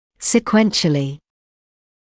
[sɪ’kwenʃəlɪ][си’куэншэли]последовательно, один за другим